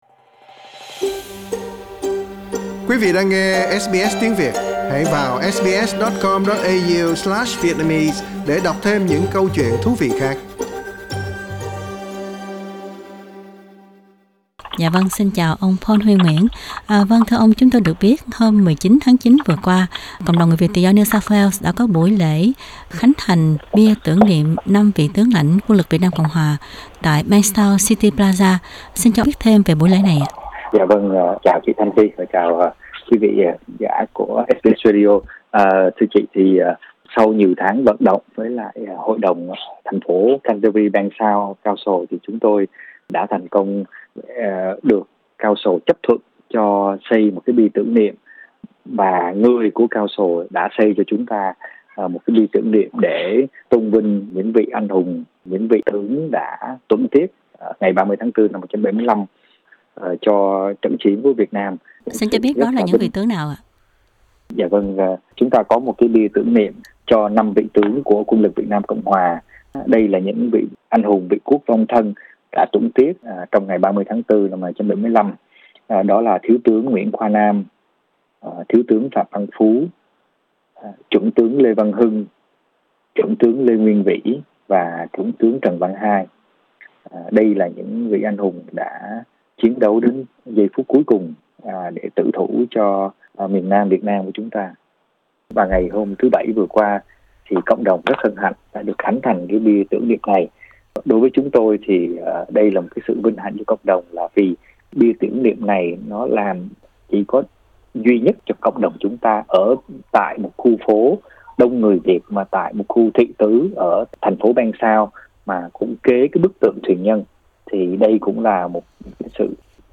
Trong phần phỏng vấn đầu trang